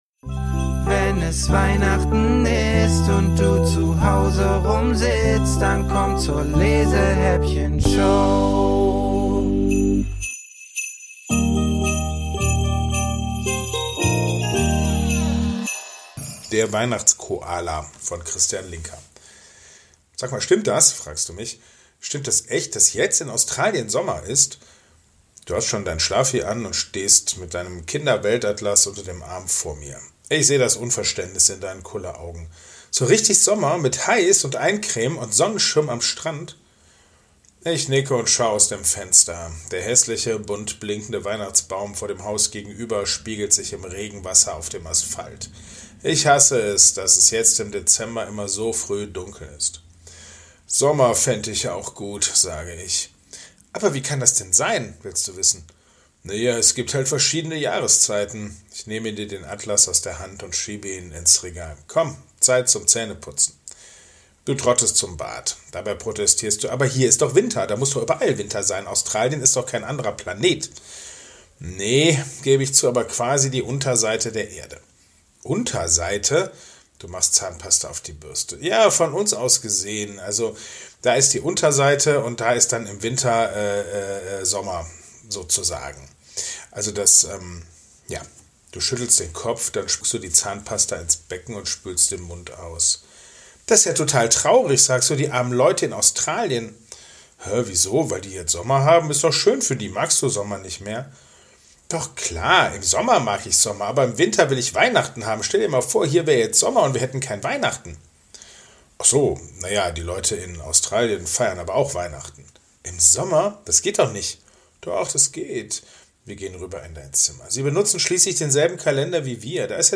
Alle Jahre wieder ist Weihnachtsstimmung bei BÜCHERALARM – diesmal